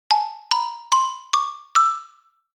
Звуки мультяшных шагов
Шаги мультяшные для видео